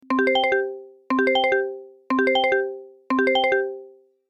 Download Phone Ringing sound effect for free.
Phone Ringing